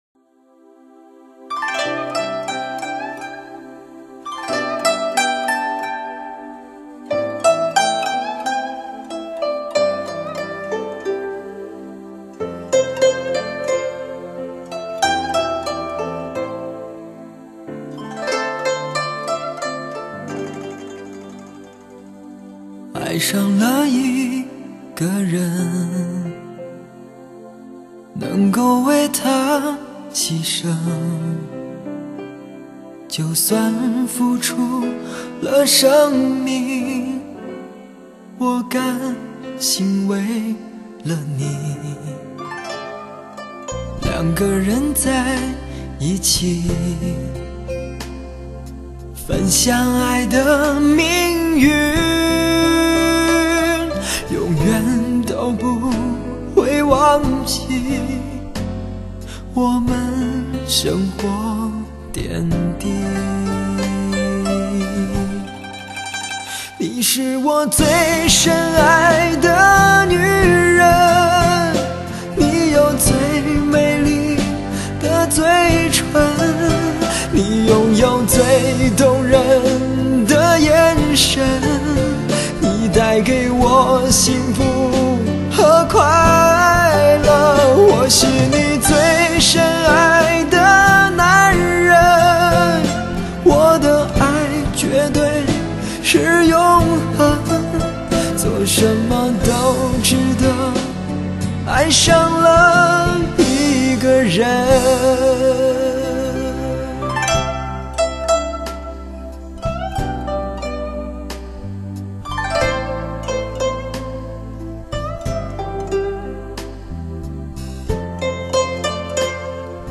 他沙哑迷人的声线和真情流露以及展现在专辑里的音乐才华和天赋，